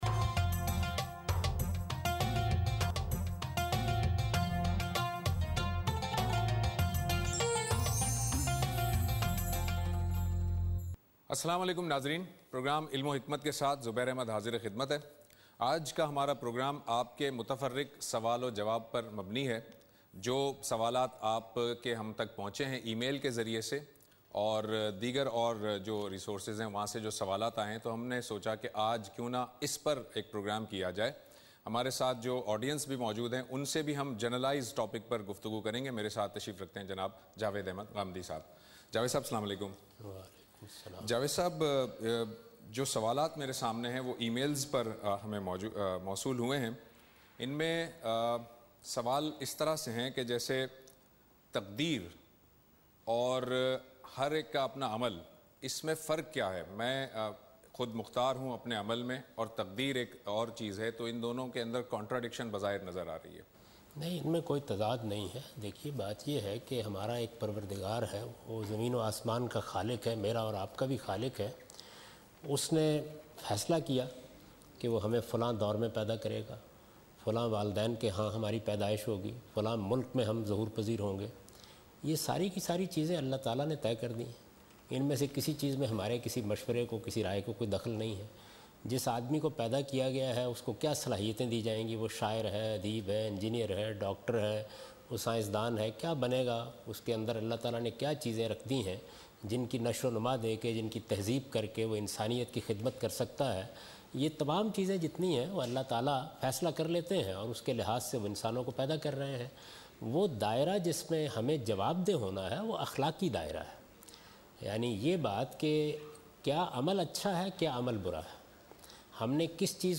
In this program Javed Ahmad Ghamidi answers the questions about miscellaneous issues.